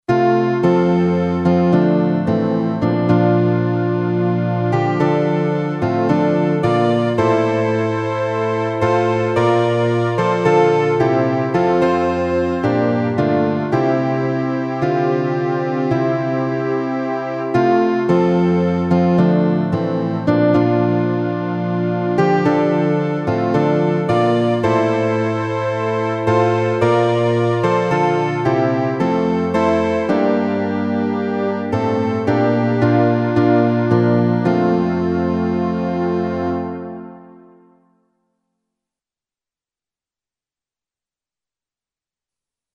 A quiet hymn of gratitude for God's peace.